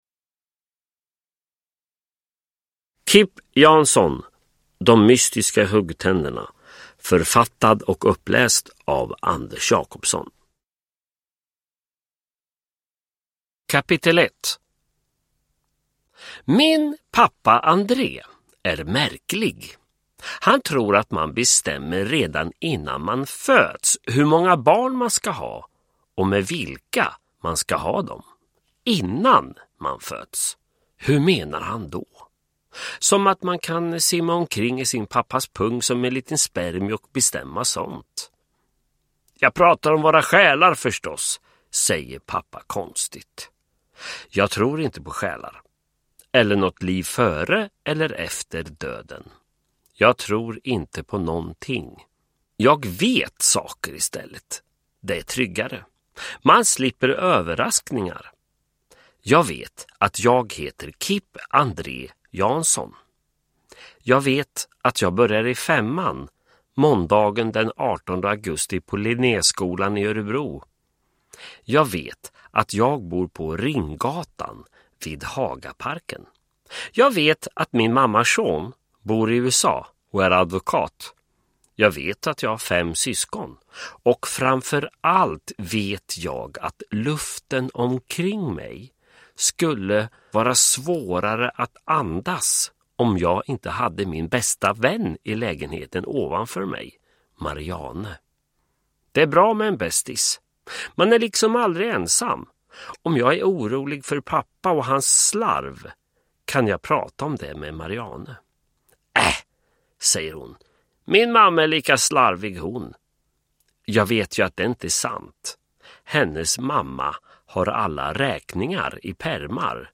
De mystiska huggtänderna – Ljudbok
Uppläsare: Anders Jacobsson